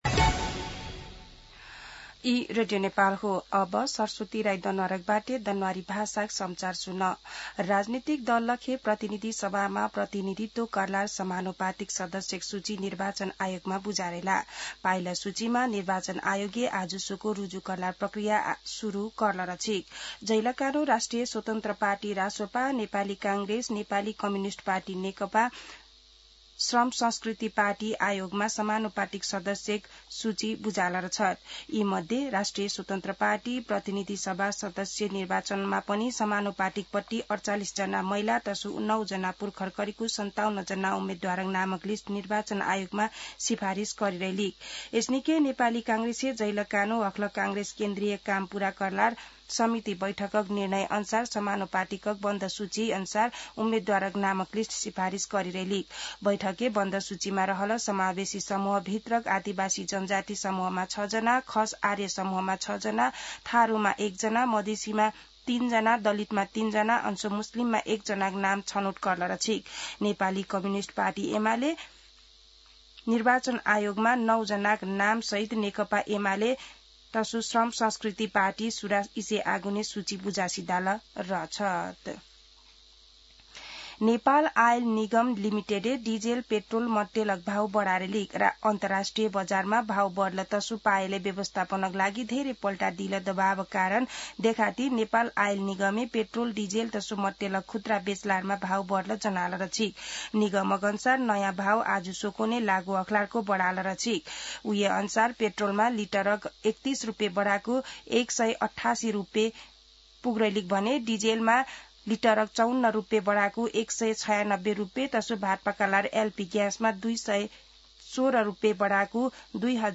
दनुवार भाषामा समाचार : २ चैत , २०८२
Danuwar-News-02.mp3